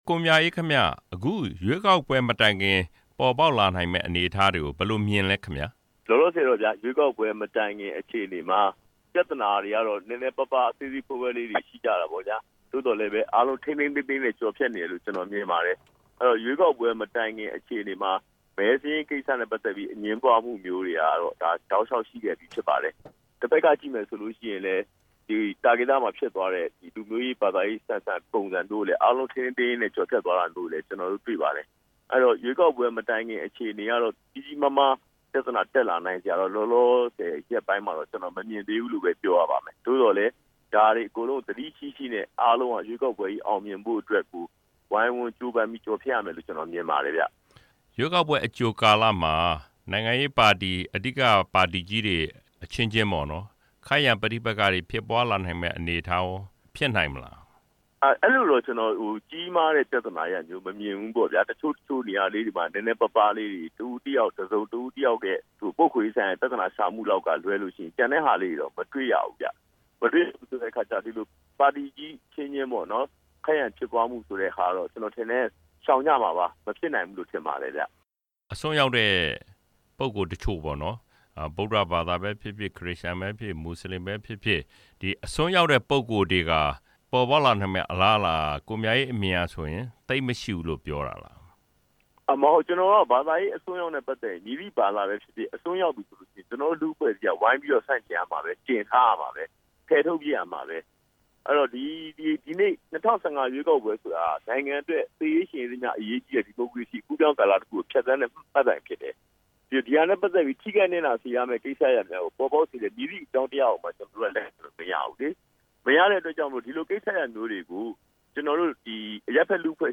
၈၈ မျိုးဆက်ခေါင်းဆောင်တစ်ဦးဖြစ်တဲ့ ကိုမြအေးနဲ့ မေးမြန်းချက်